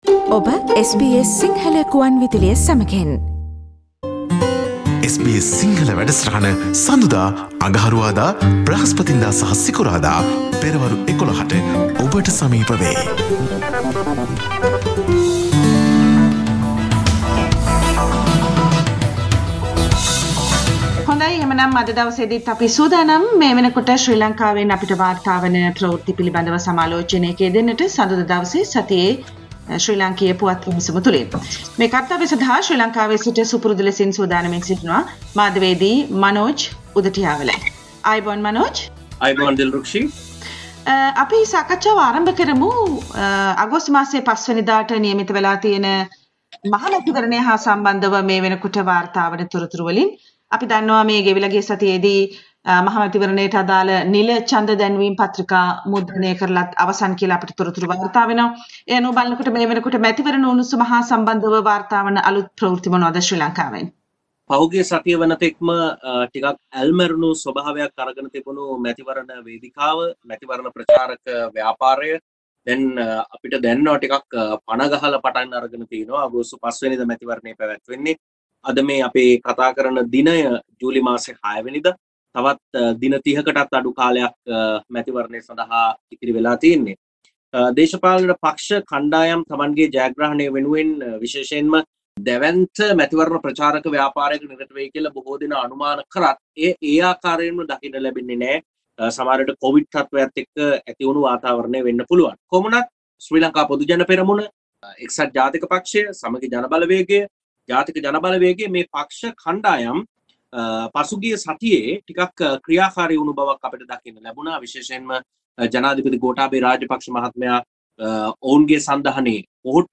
Sri Lanka General election 2020 Source: SBS Sinhala radio